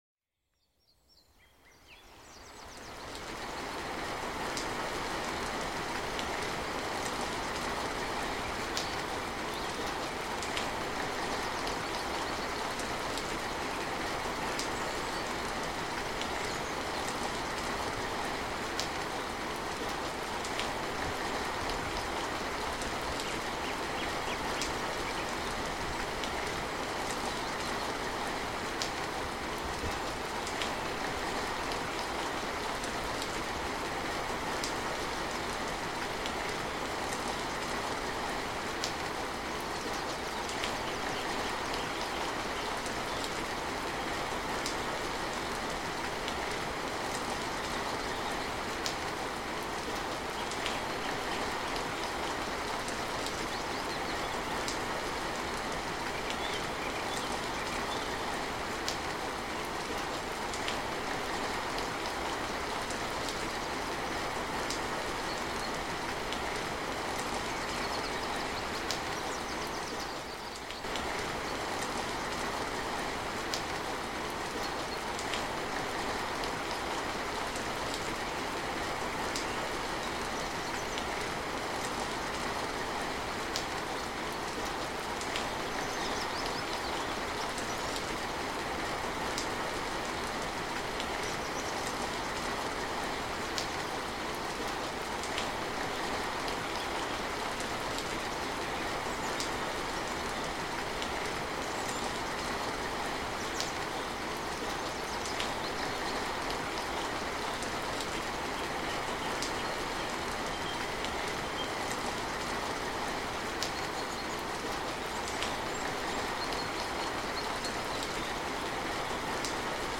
Rainforest Harmony for Deep Relaxation – Relaxing Mind Journey – A Tranquil Escape
Each episode of Send Me to Sleep features soothing soundscapes and calming melodies, expertly crafted to melt away the day's tension and invite a peaceful night's rest.